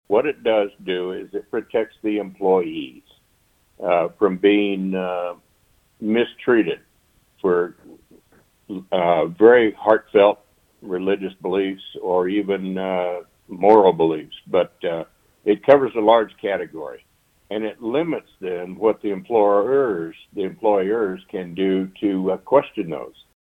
51st District Representative Ron Highland of Wamego voted for the bill. He says this will shield employees unwilling to get vaccinated against COVID, regardless of the reason.